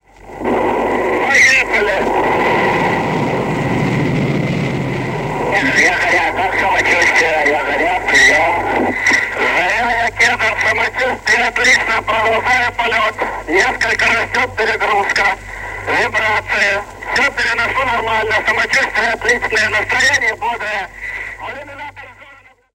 На этой странице собраны звуки космических ракет: от рева двигателей при старте до гула работы систем в открытом космосе.
Первый звук слова Юрия Гагарина в космической ракете